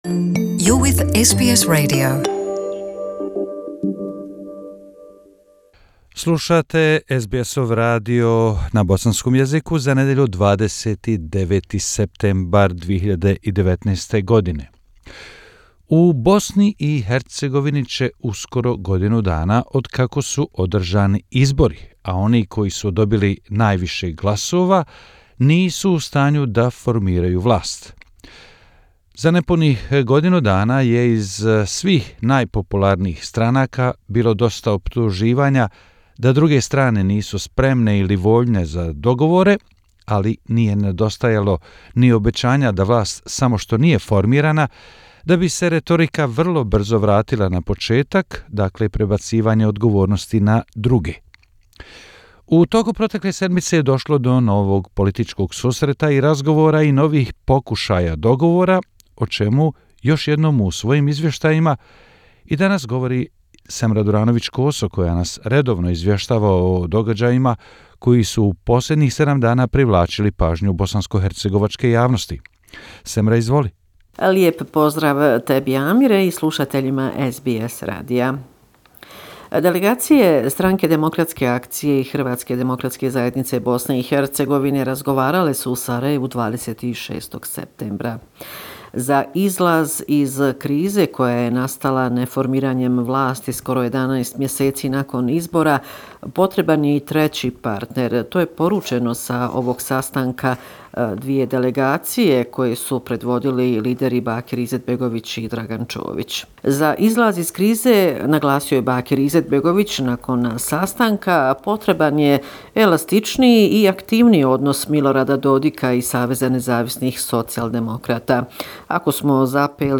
Bosnia and Herzegovina - affairs in the country for the last seven day, weekly report September 29, 2019